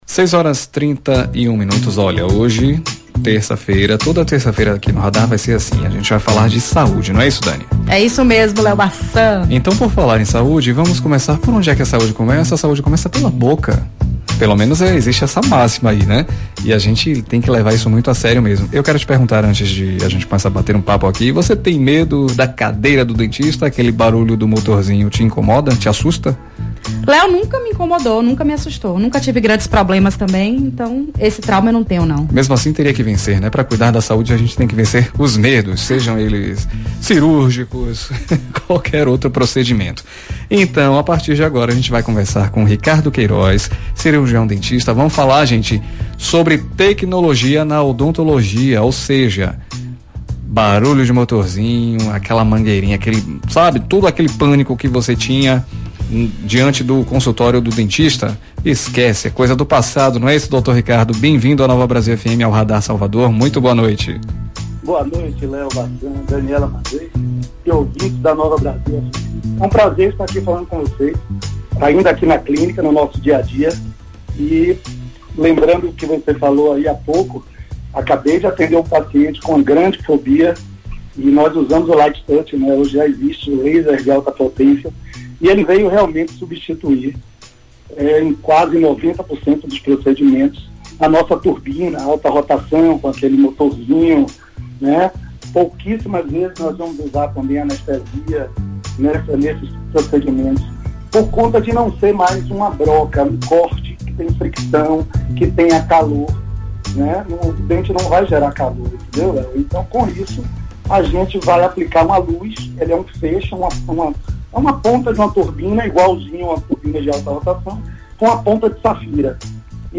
Programa RADAR SALVADOR – ENTREVISTA